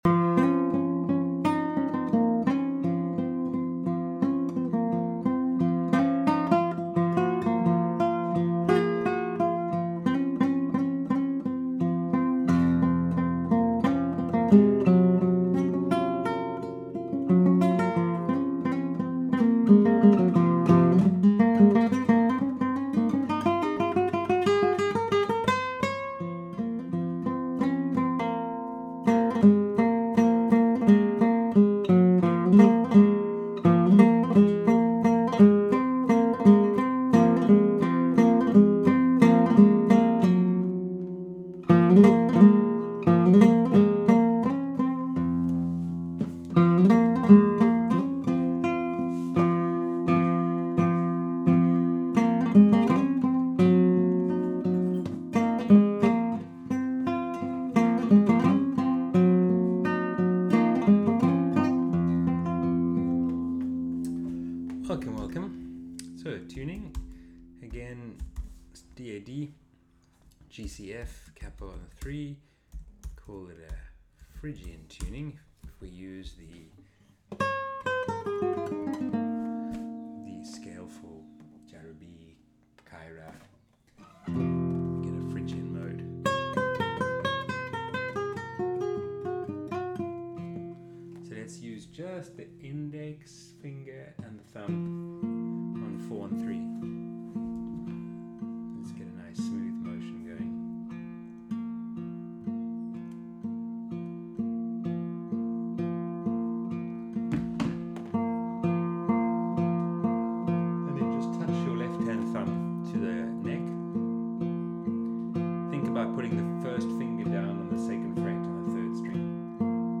Si Naani 21 Nov 2024 | Playing Guitar with Derek Gripper
Video lessons uploaded after every online group class.